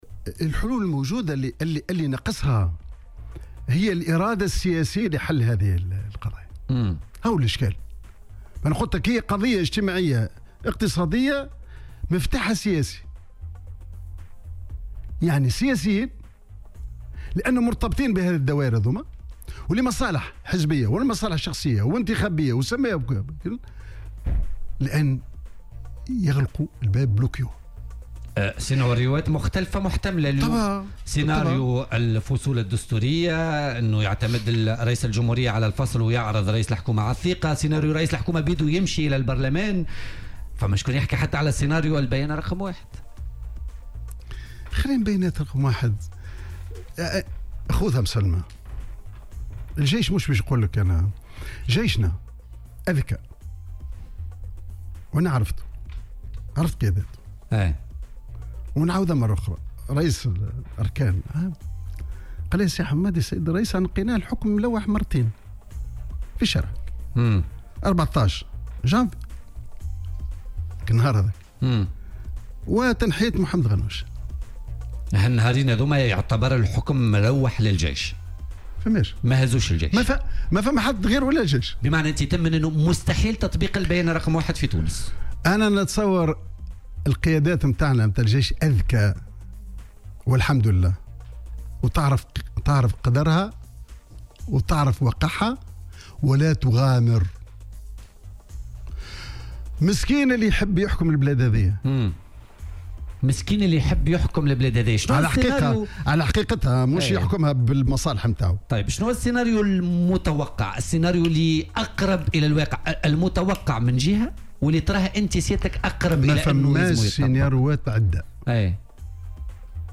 وأضاف خلال استضافته في برنامج "بوليتيكا" اليوم الخميس 28 جوان 2018، أن الكواليس تتحدث عن مساع لتأجيل الانتخابات الرئاسية بسنة ونصف أو بسنتين، متسائلا "لصالح من هذا التأجيل؟".